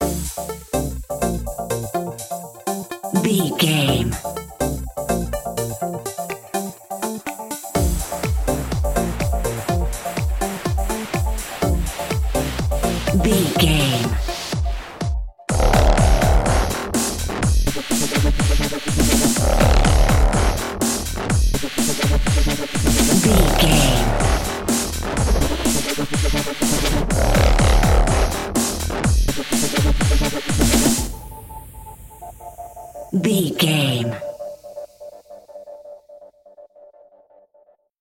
Fast paced
Aeolian/Minor
aggressive
powerful
dark
driving
energetic
intense
piano
drum machine
synthesiser
breakbeat
synth leads
synth bass